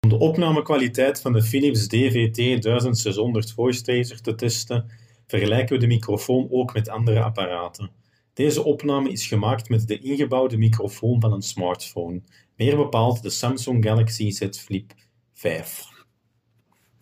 Audio clip 6 (Samsung Galaxy Z Flip 5)